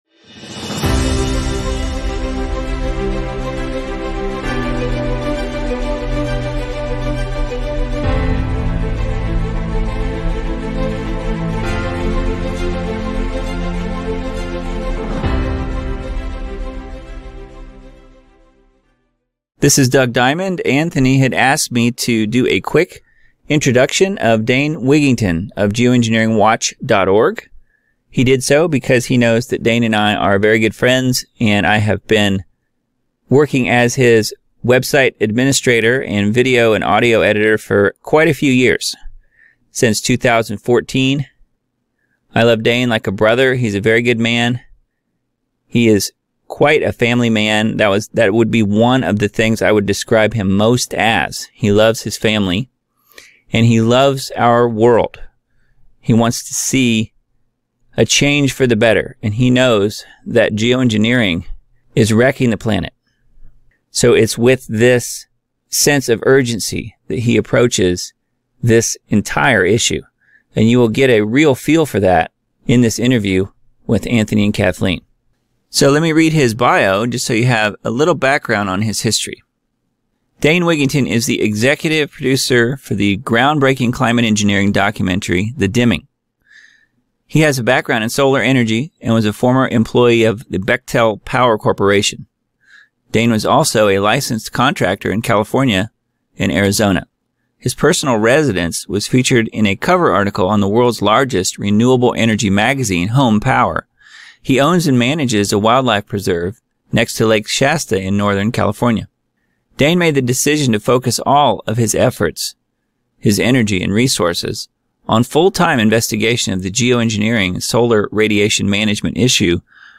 A Geoengineering Discussion
(FULL INTERVIEW)